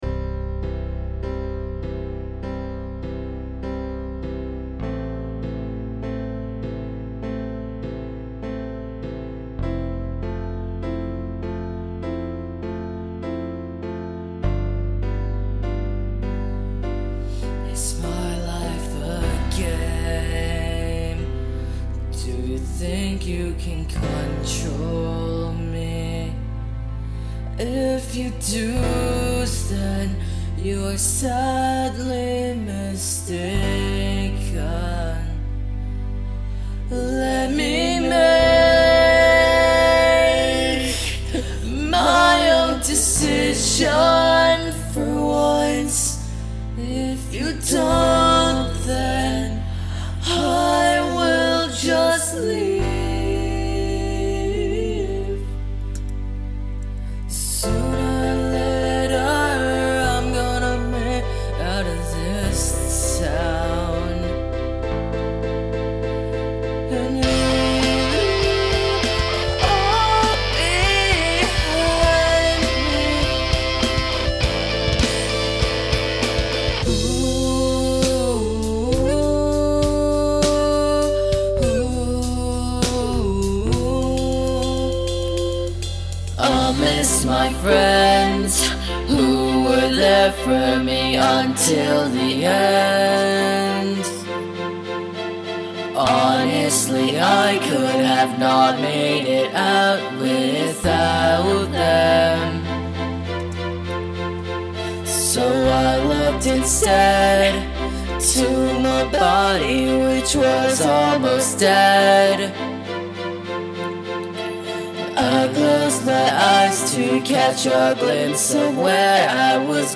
Rock/Hard-rock